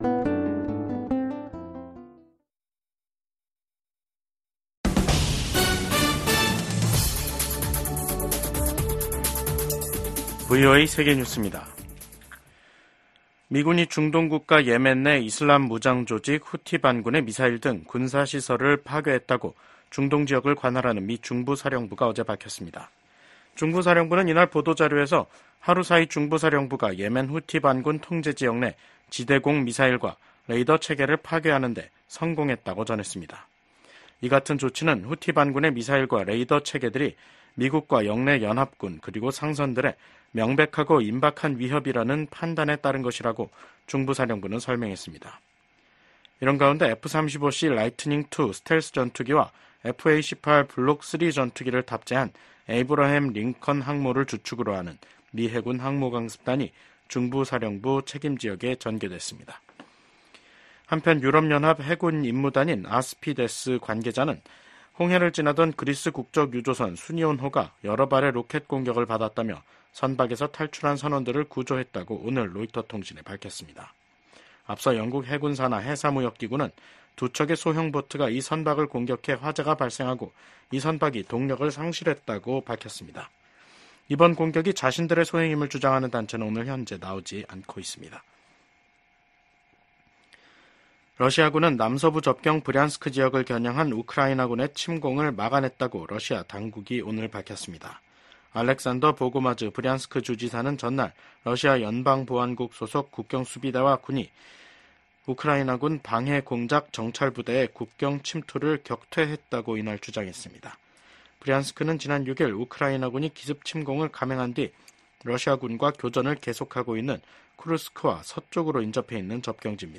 VOA 한국어 간판 뉴스 프로그램 '뉴스 투데이', 2024년 8월 22일 2부 방송입니다. 미국 국방부는 미한 연합훈련인 을지프리덤실드 연습이 방어적 성격이란 점을 분명히 하며 ‘침략 전쟁 연습’이란 북한의 주장을 일축했습니다. 주한 미 공군 전투기들이 23일까지 준비태세훈련을 실시합니다. 미국 정부가 윤석열 한국 대통령의 대북 접근 방식을 지지한다고 밝혔습니다.